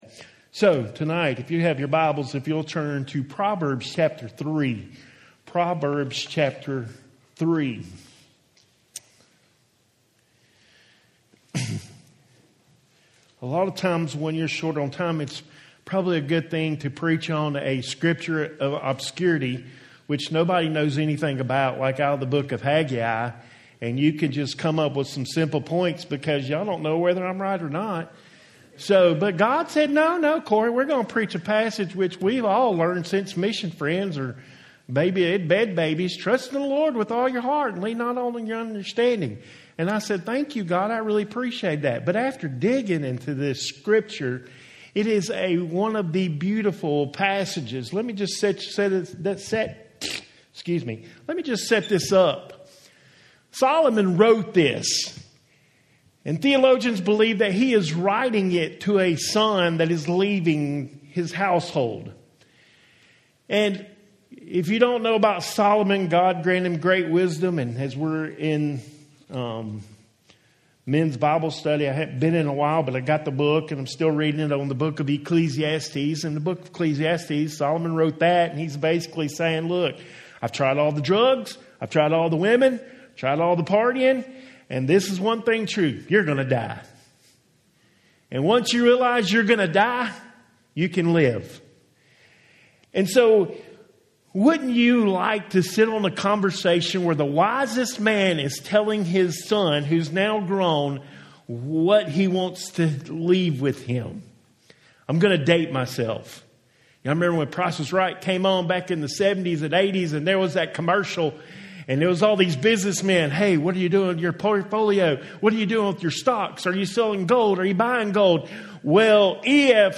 Home › Sermons › Father And Son Conversations